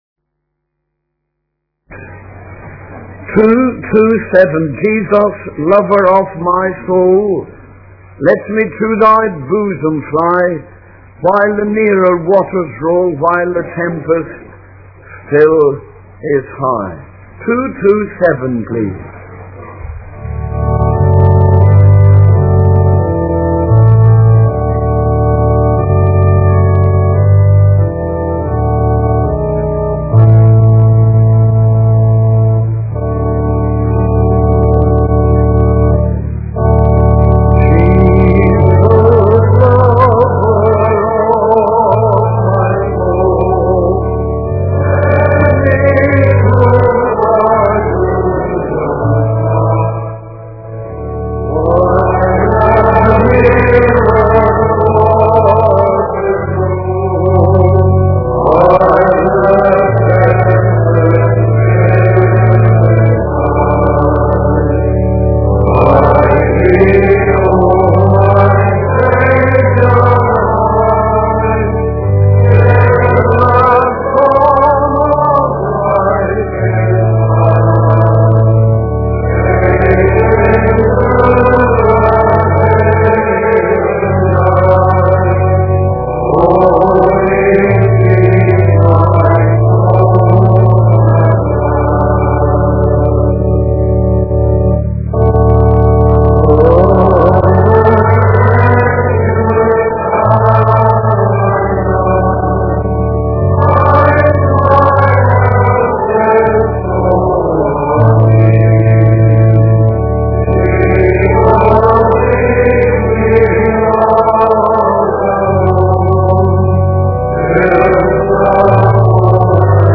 In this sermon, the preacher discusses the concept of time and how it relates to the end times.